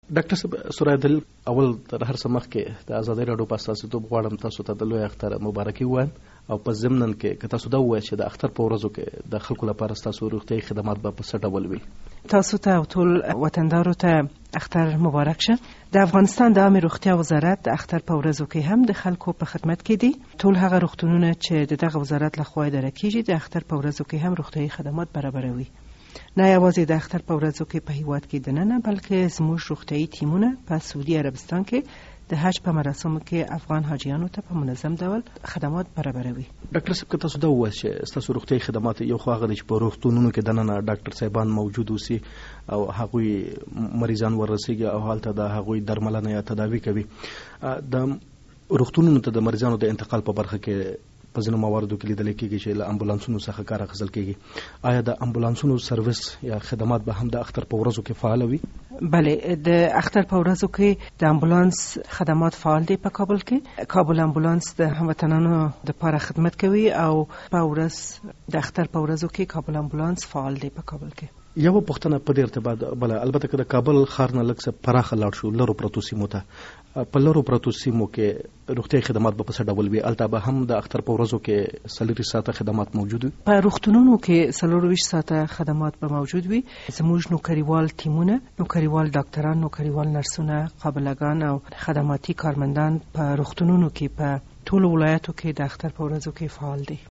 له ډاکټر ثریا دلیل سره مرکه